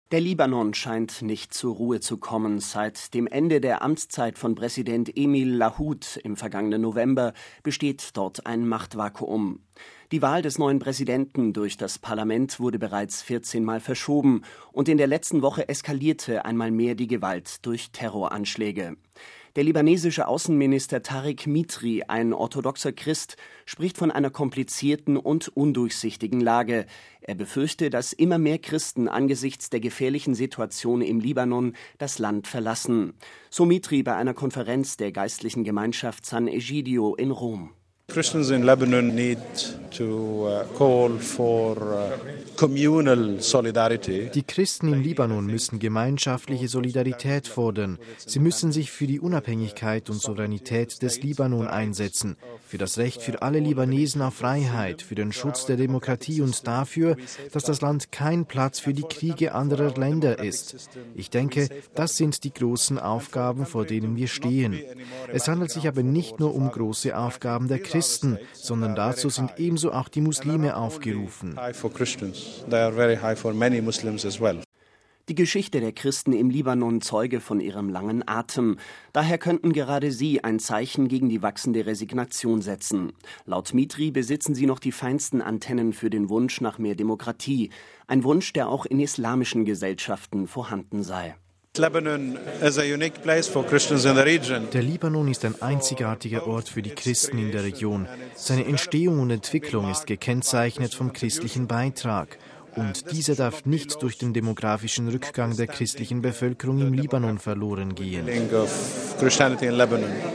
Der libanesische Außenminister Tareq Mitri, ein orthodoxer Christ, spricht von einer komplizierten und undurchsichtigen Lage. Er befürchte, dass immer mehr Christen angesichts der gefährlichen Situation im Libanon das Land verlassen – so Mitri bei einer Konferenz der geistlichen Gemeinschaft Sant´Egidio in Rom: